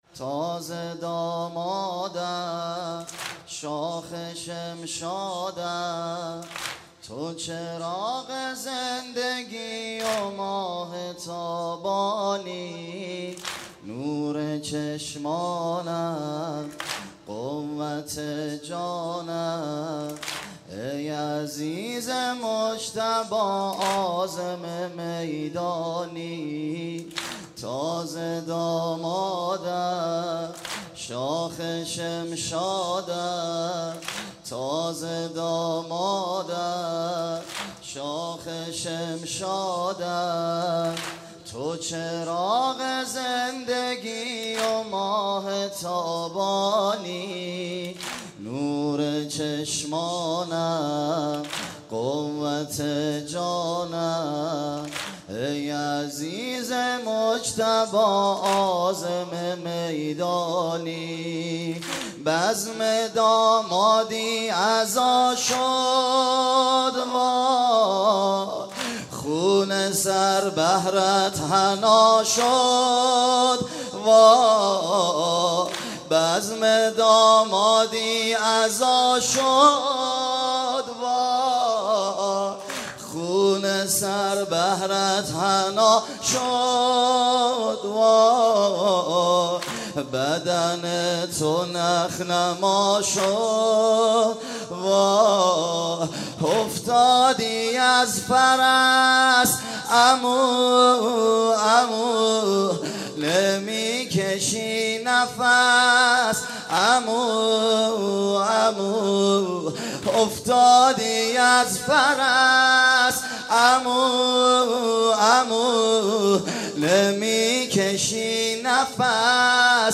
گلچین محرم 95_واحد_تازه دامادم شاخ شمشادم